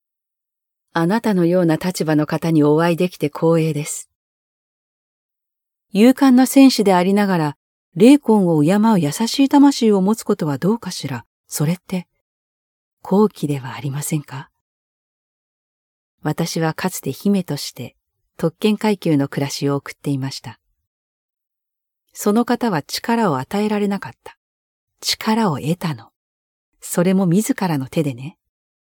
Natürlich, Vielseitig, Zuverlässig, Warm, Sanft
Persönlichkeiten
Her voice is known for its empathetic and believable quality, making it ideal for connecting with audiences on a deeper level.